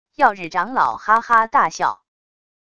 曜日长老哈哈大笑wav音频